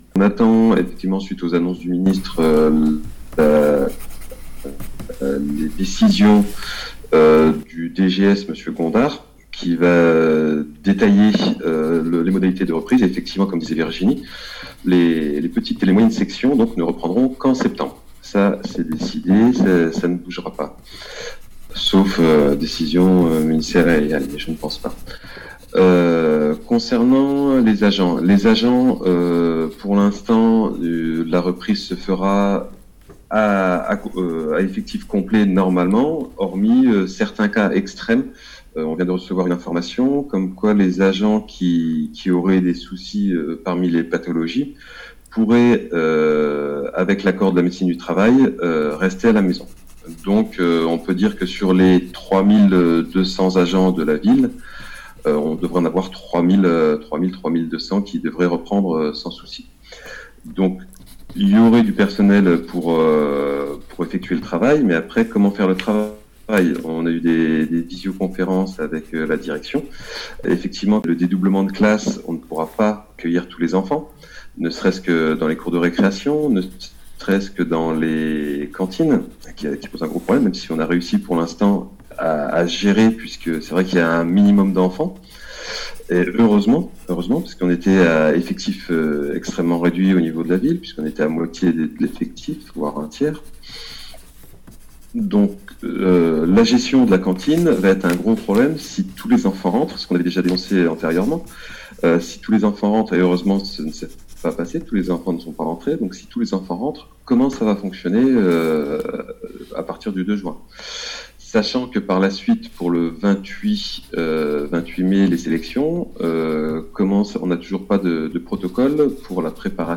Entretiens.